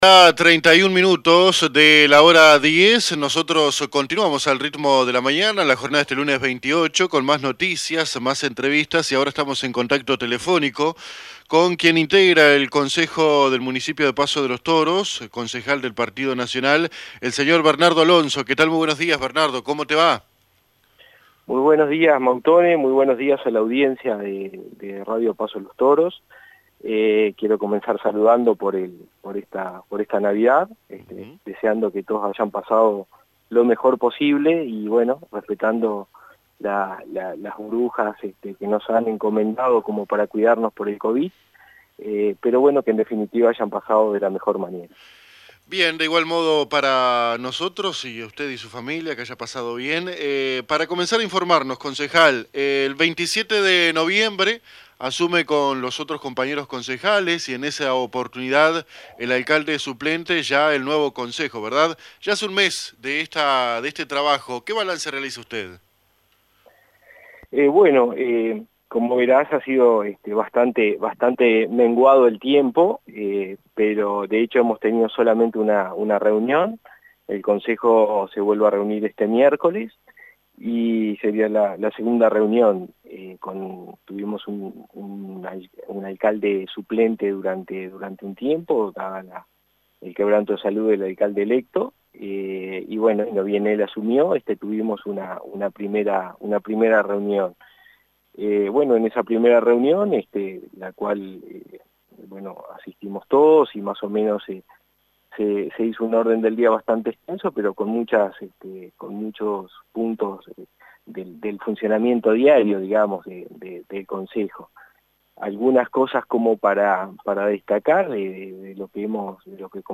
El Concejal por el PN del Municipio de Paso de los Toros, Bernardo Alonso, se expresó a AM 1110 en la jornada de hoy saludando a la población por la pasada Navidad, y en la oportunidad hizo un balance a un mes de haber asumido el cargo junto a todo el Concejo Municipal y el Alcalde interino el 27 de noviembre.